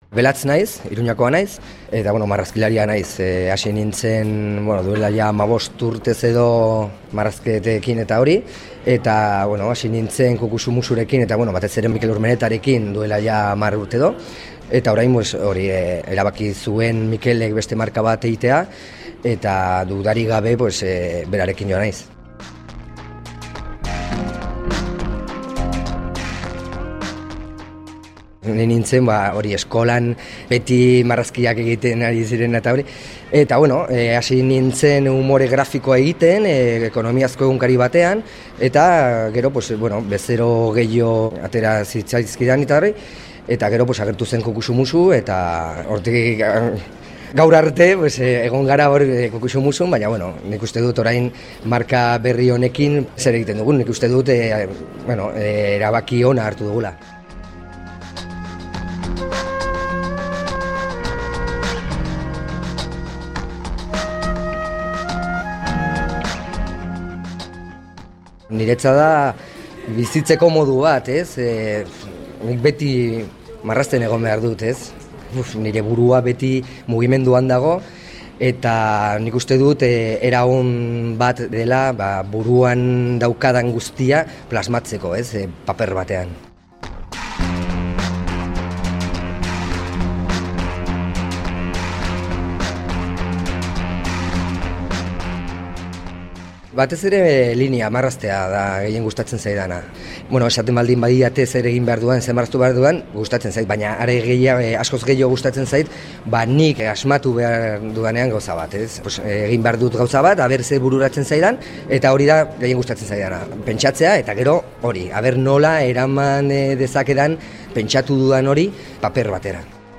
elkarrizketatu baitute.